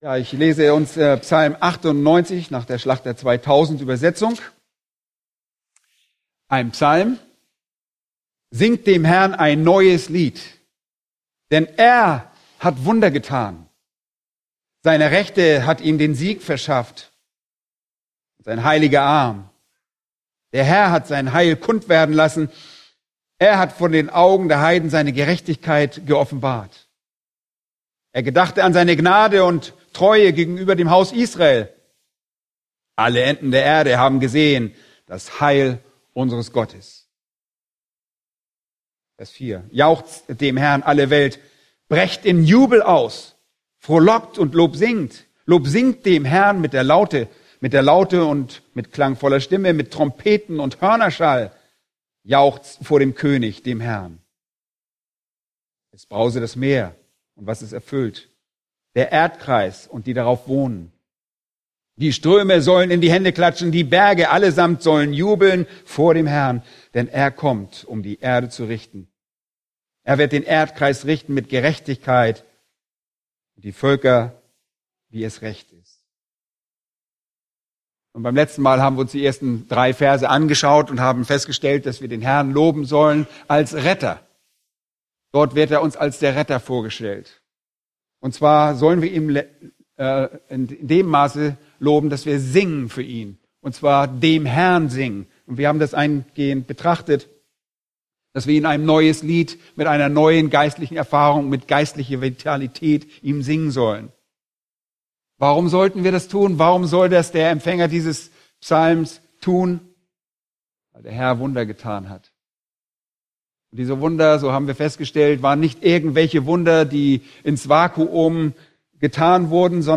Eine predigt aus der serie "Psalm 98." Psalm 98,4-6